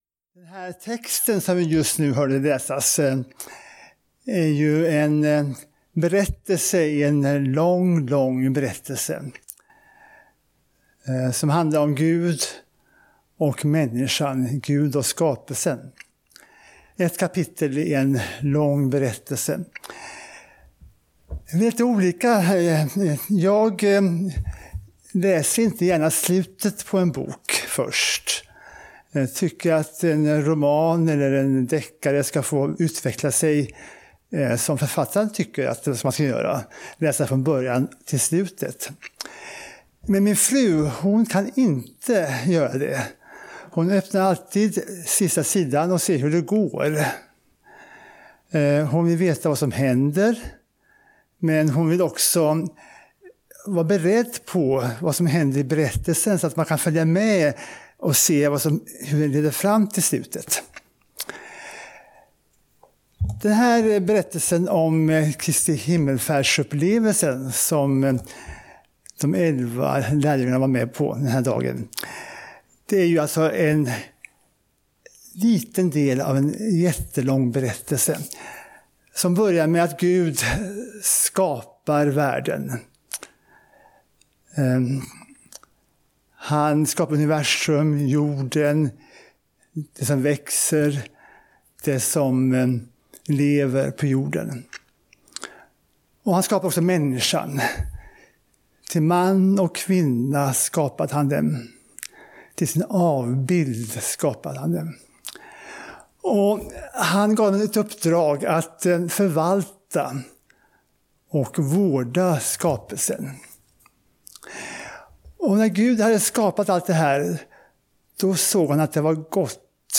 Gudstjänst i Tabor i Rumskulla.